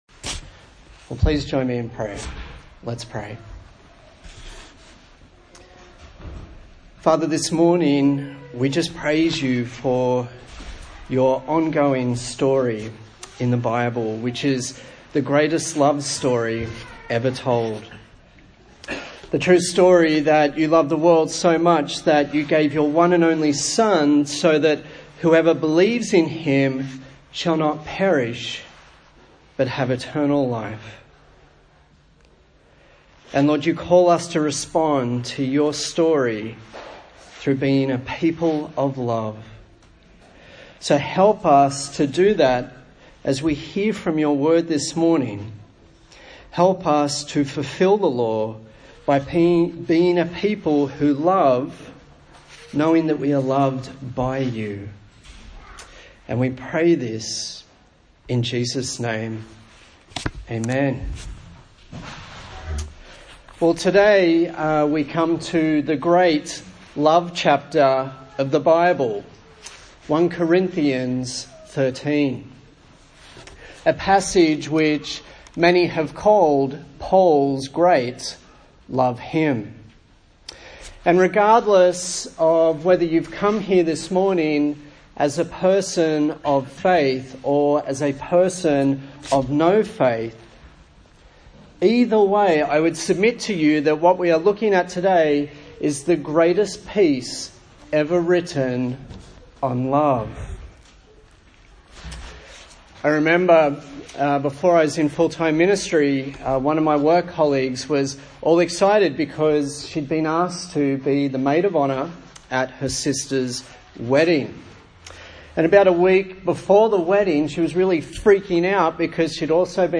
A sermon in the series on 1 Corinthians
Service Type: Sunday Morning